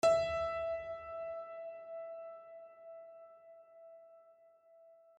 LoudAndProudPiano
e4.mp3